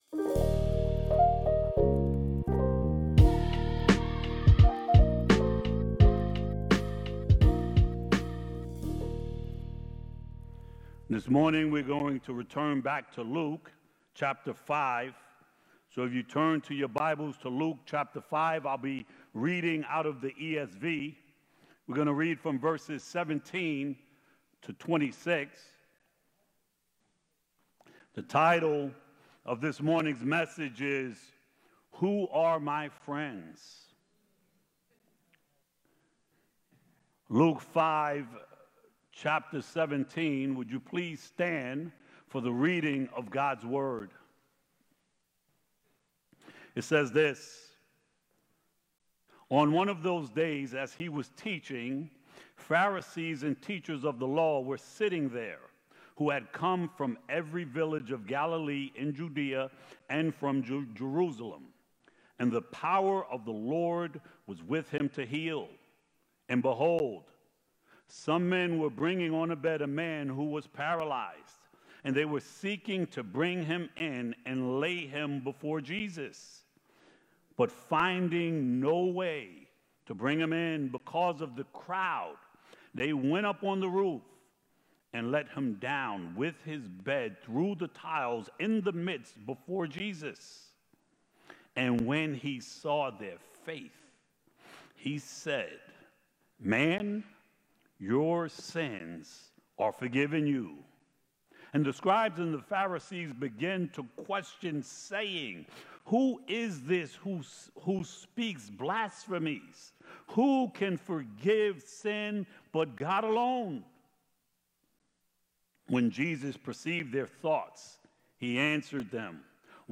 Sermons from Light of the World Church in Minisink Hills, PA